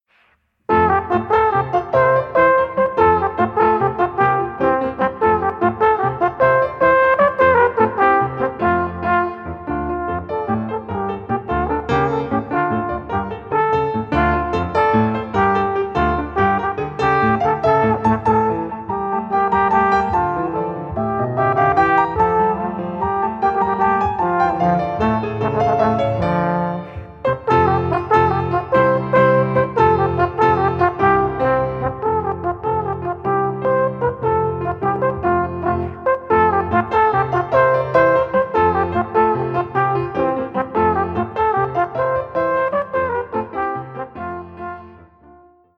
Alphorn in F, Klavier